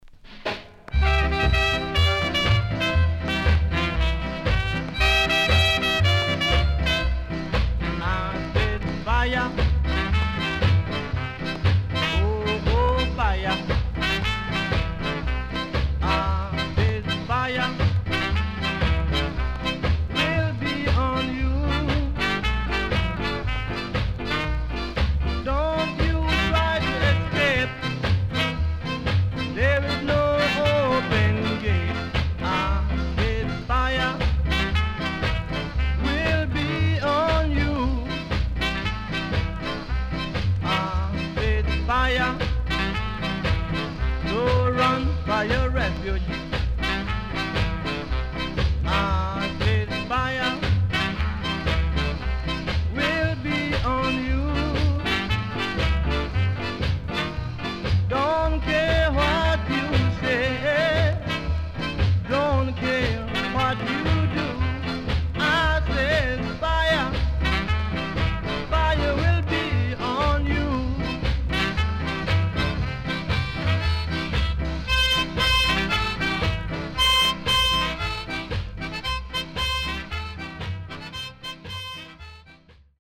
HOME > SKA / ROCKSTEADY  >  SKA  >  EARLY 60’s
Nice Early Ska Vocal
SIDE A:うすいこまかい傷ありますがノイズあまり目立ちません。